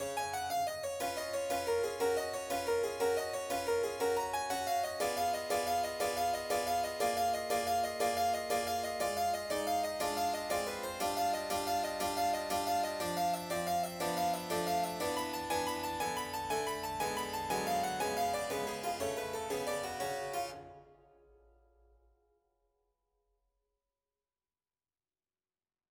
스카를라티 소나타 K319, 마디 62–80